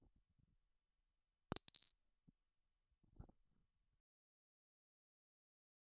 Bullet shell dropping
bullet bullet-shell ching clink ding dink drop round sound effect free sound royalty free Sound Effects